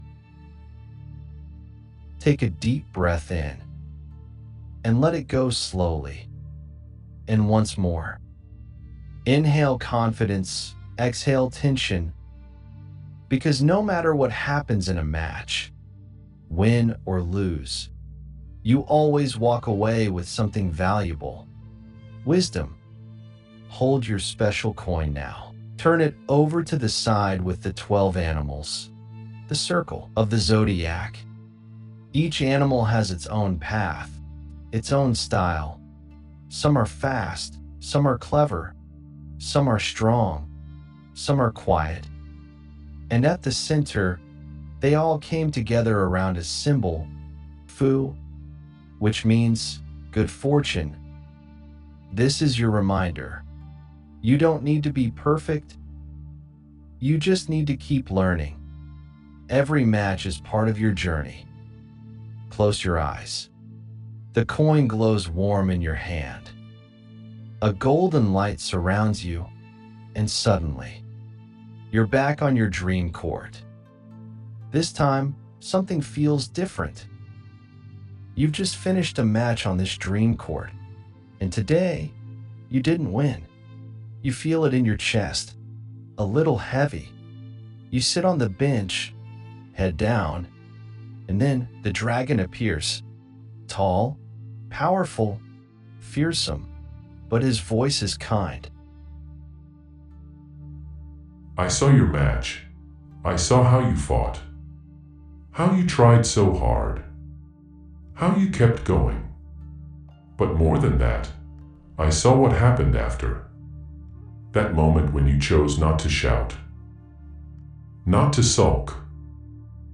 Discover the ultimate mindfulness toolkit designed for junior tennis players aged 8-15 with "Unlock the Champion Within." This 3-part mindset training experience helps young athletes build mental toughness, match-day calm, and true sportsmanship. Featuring professionally narrated audio recordings (4 to 6 minutes), a twin-sided mindfulness coin, a zodiac strengths card, a mini quiz card, and a parent guide, this guided journey enhances focus, resilience, and performance.